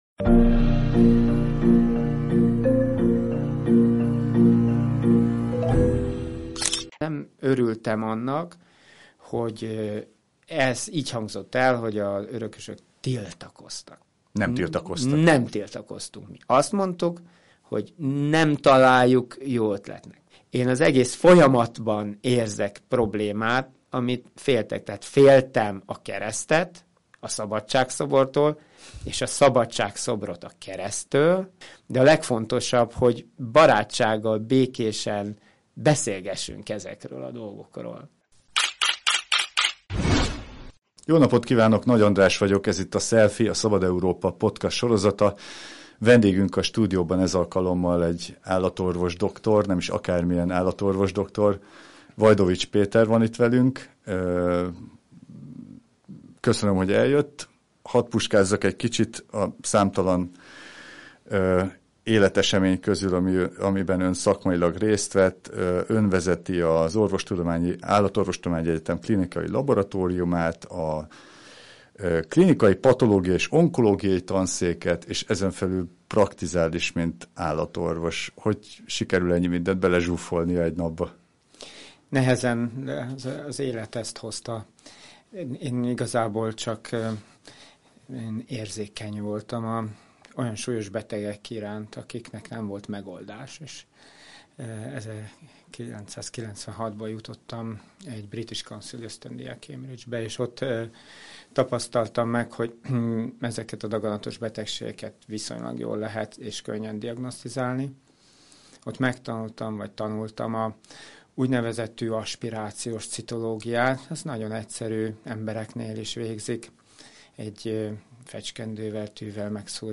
Az állatorvosi hivatásról, hitről, az élet szeretetéről és a Szabadság-szoborról beszélgettünk.